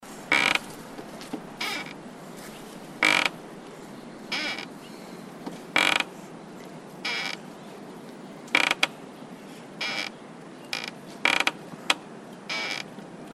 Скрип старых деревянных качелей